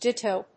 /díṭoʊ(米国英語), dítəʊ(英国英語)/